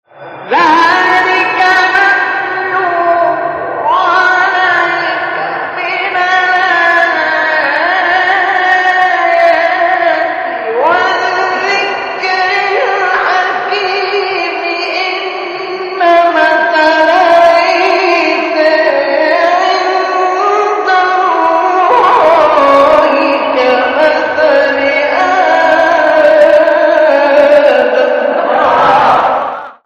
سوره : آل عمران آیه: 58-59 استاد : مصطفی اسماعیل مقام : مرکب خوانی(صبا * چهارگاه) قبلی بعدی